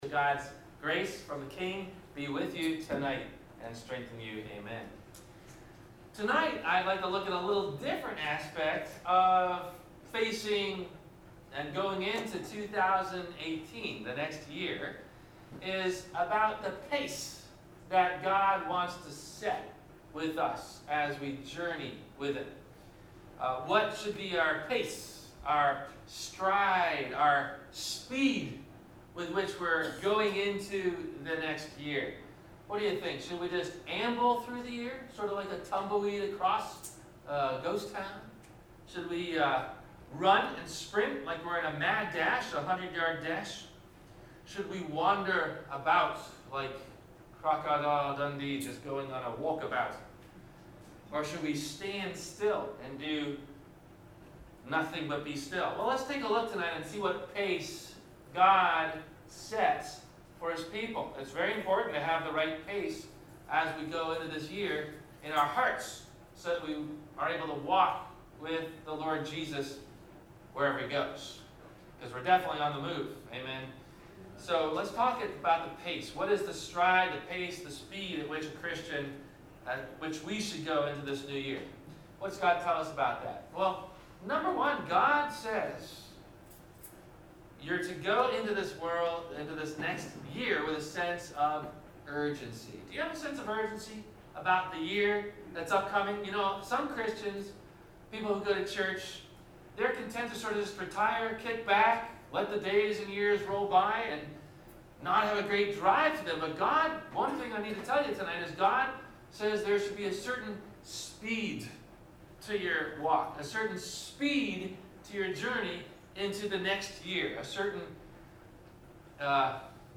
Scriptures below from the church bulletin used in the Sermon: (coming soon)